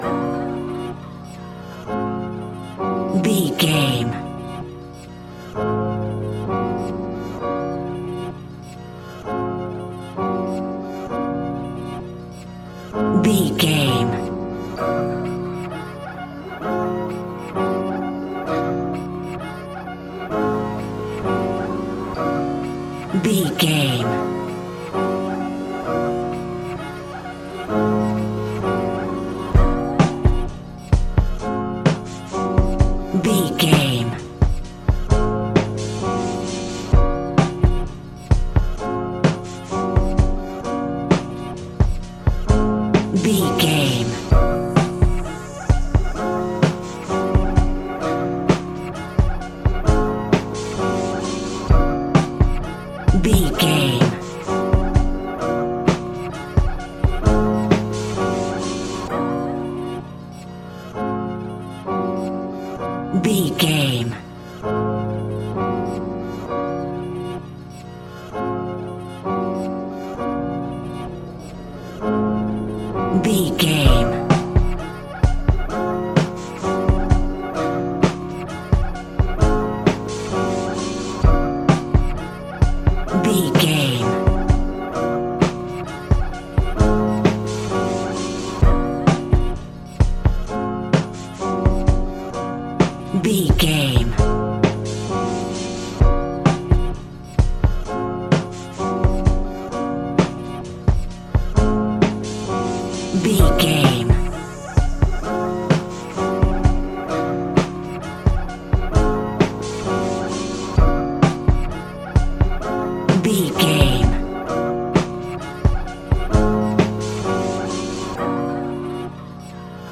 Ionian/Major
drums
dreamy
smooth
mellow